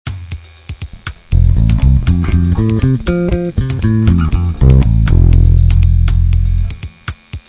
LES ARPEGES D'ACCORDS DANS LE TRAVAIL DE LA BASSE
Et en plus , les arpèges d'accords parfait majeur
parfait_majeur.wav